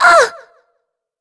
Shea-Vox_Damage_02.wav